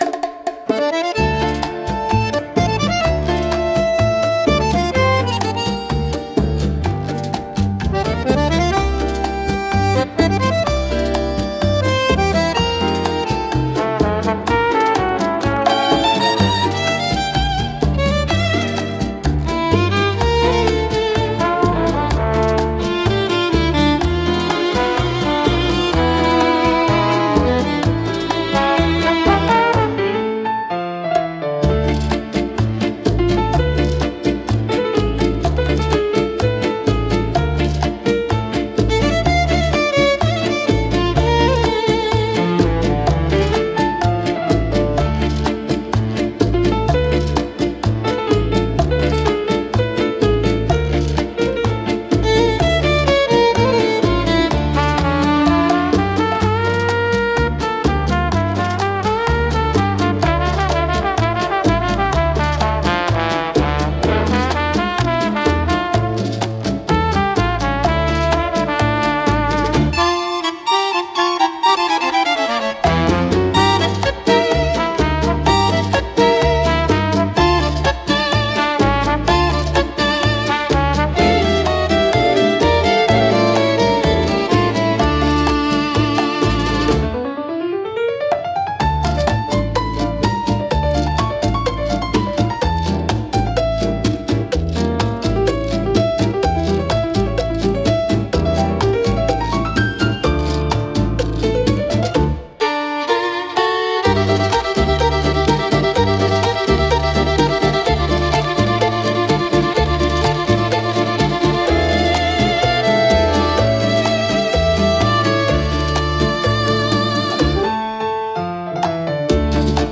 música e arranjo: IA) instrumental 7